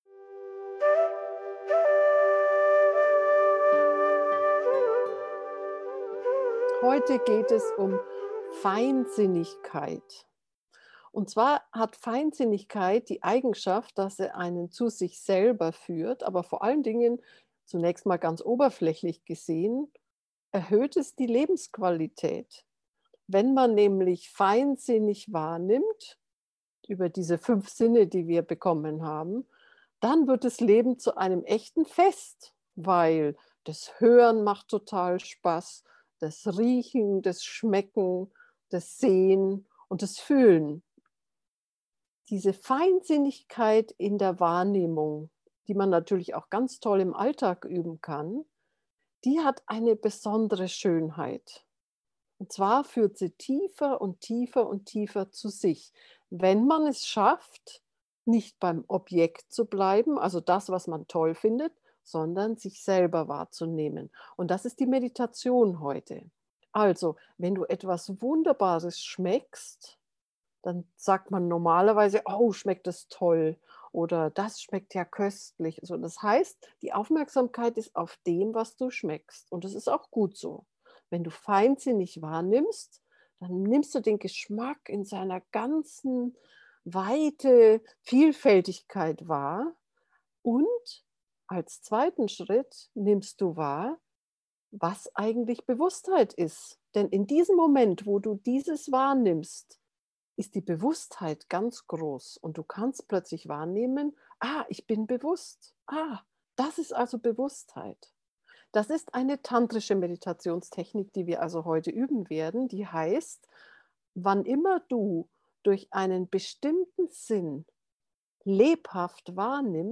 alltagsmeditation-sinne-gefuehrte-meditation